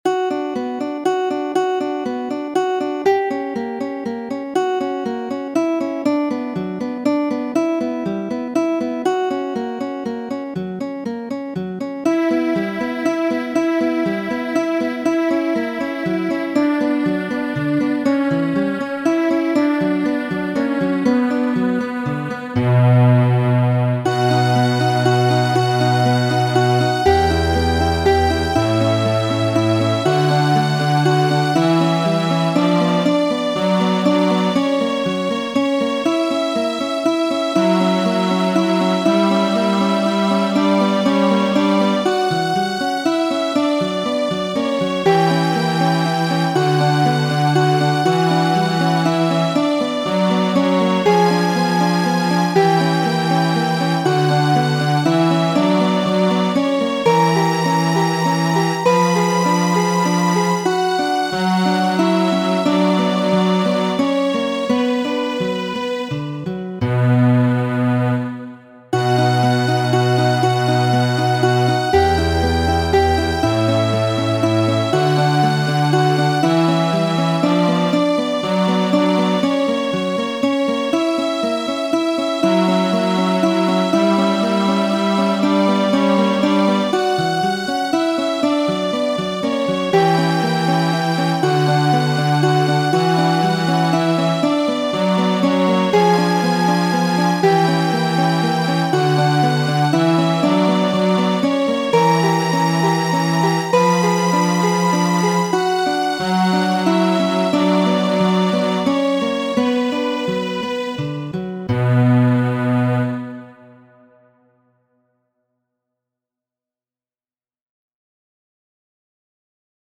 kadre de sia Dudek kvar lecioj progresemaj por gitaro
orĥestrigita kaj komputgiligita